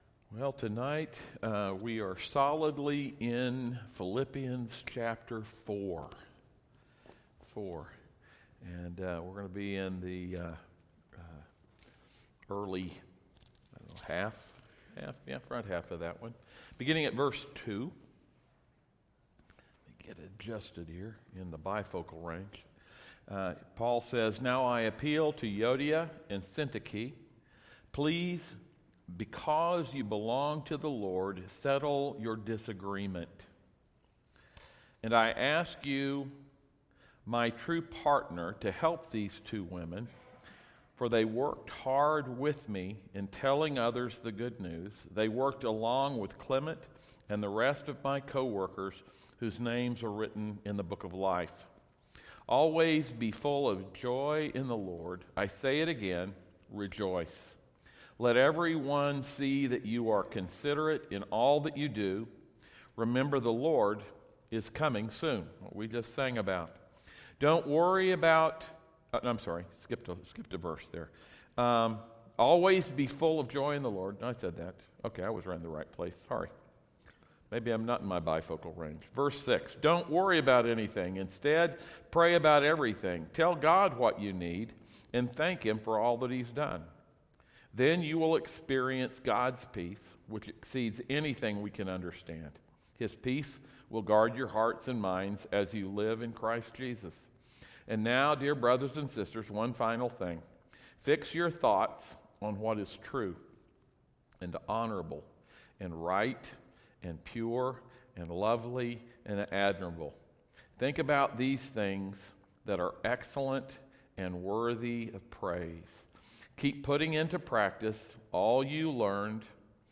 Service Type: audio sermons